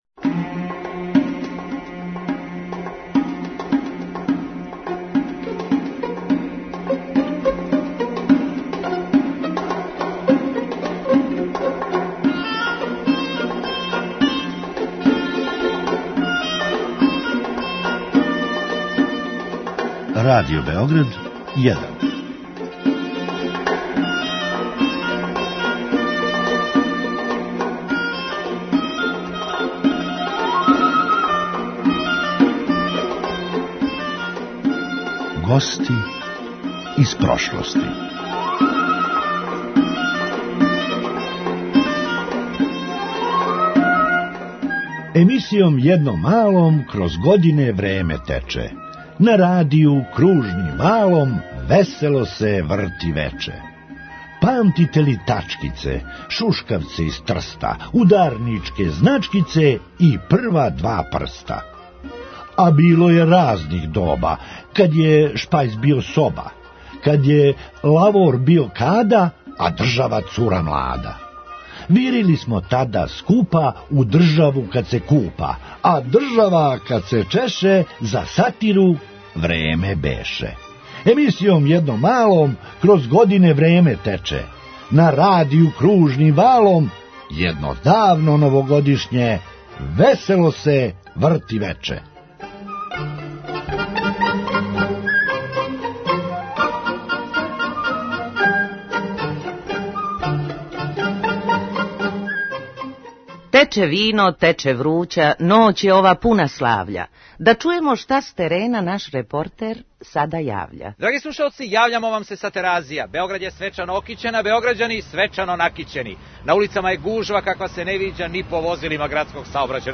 Емисија “Весело вече” емитовала се од 1949. до 2001. године и за то време испратила 52 нове године. За празничне “Госте из прошлости” приредили смо вам избор из неколико новогодишњих издања ове омиљене емисије Радио Београда, у којима су глумили: